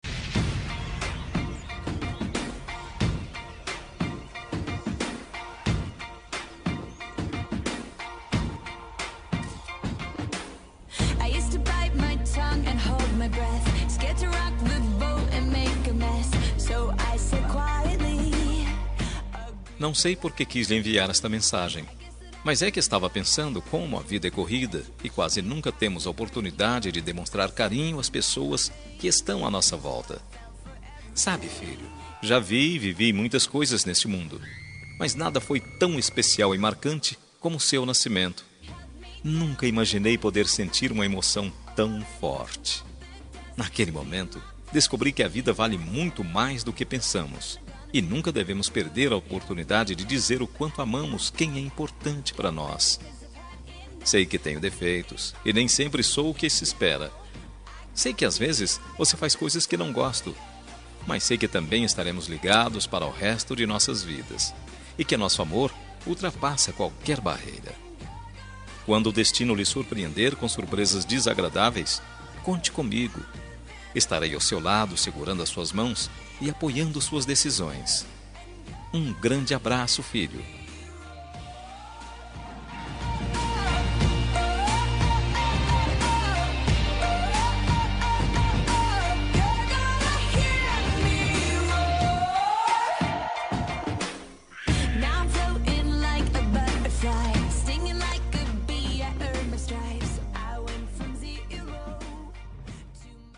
Homenagem para Filho – Voz Masculino – Cód: 8133